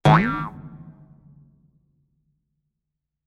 Звуки эмодзи
Дизлайк – палец вниз